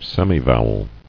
[sem·i·vow·el]